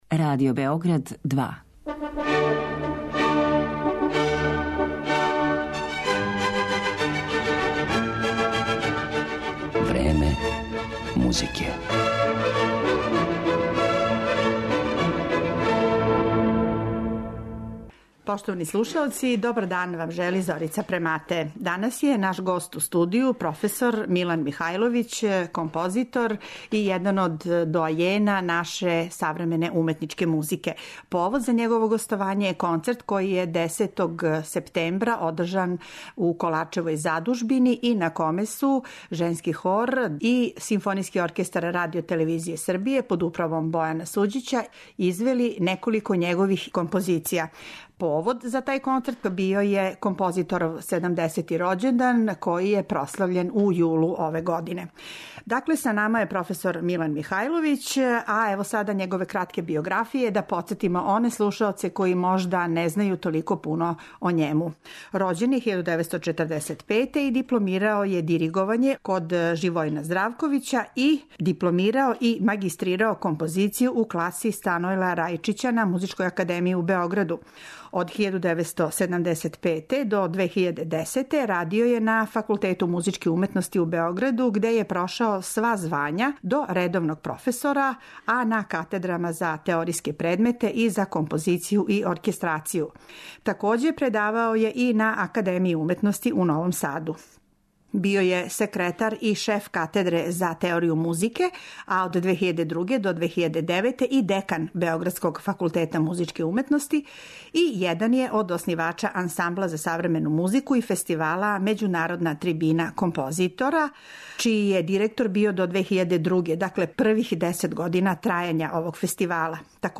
Снимке са овог концерта слушаћемо у данашњој емисији Време музике